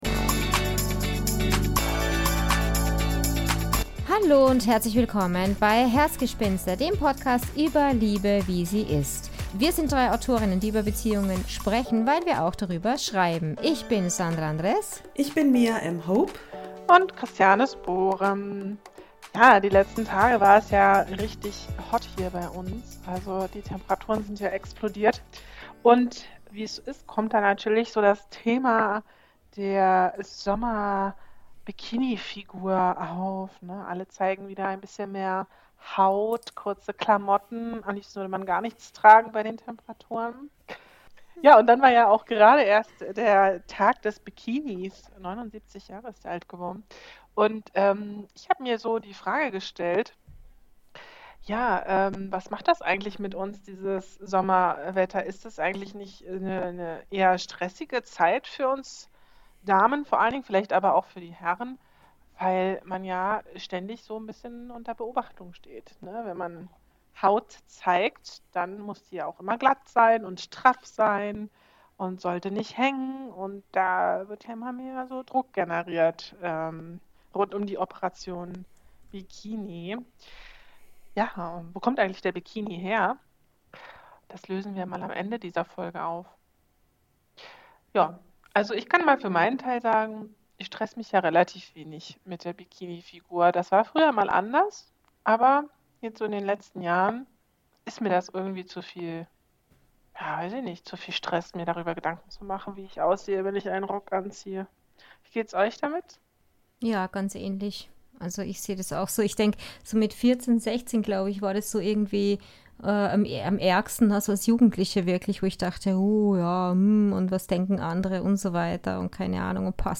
Eine lockere, spritzige Folge mit „I don’t care“-Attitüde, ganz viel Sonne im Herzen und der Erinnerung daran: Liebe ist, wie sie ist – und dein Körper auch.